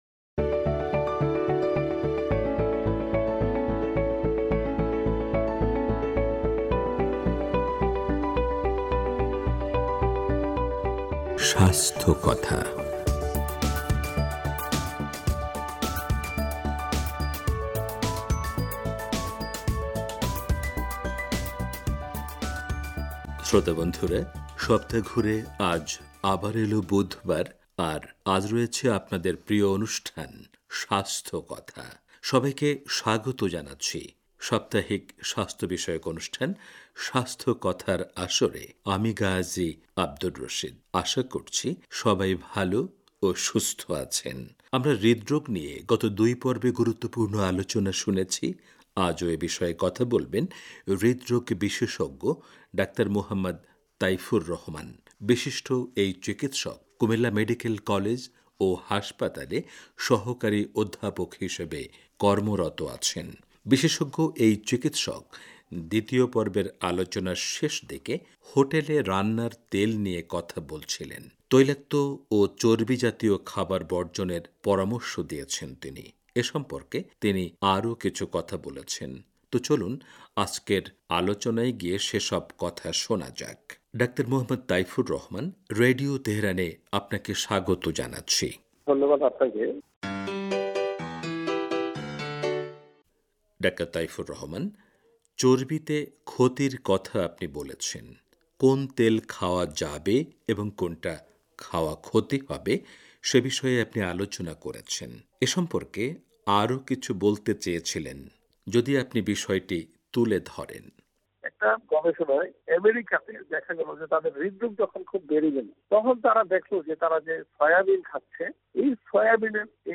স্বাস্থ্যকথা: হৃদরোগ-পর্ব ৩